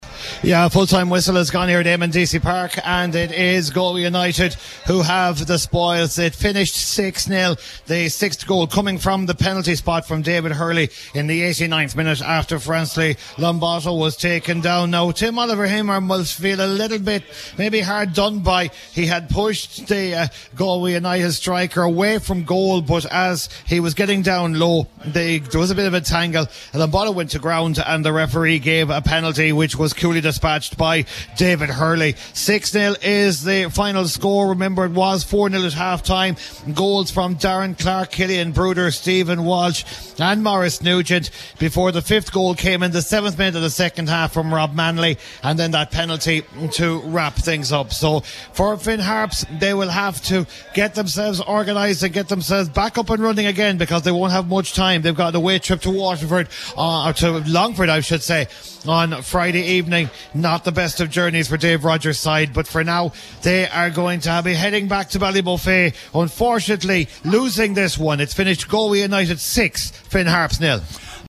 filed this full time report.